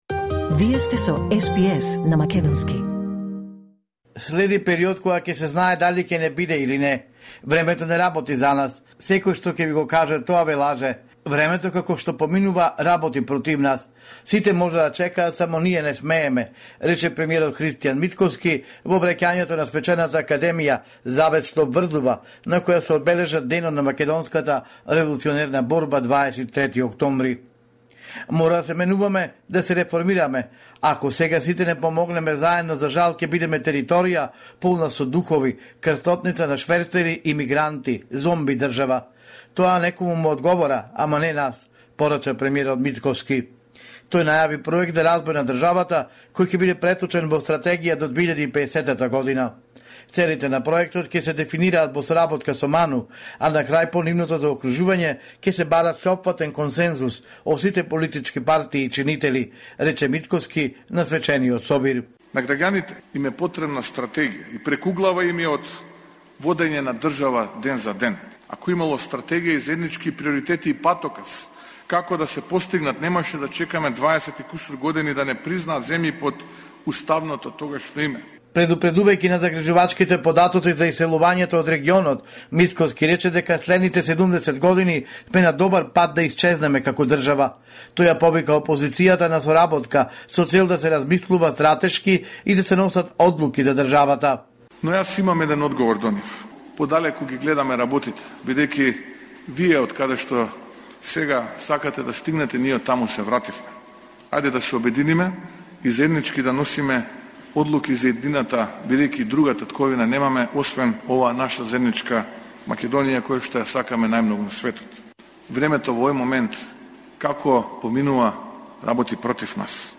Homeland Report in Macedonian 24 October 2024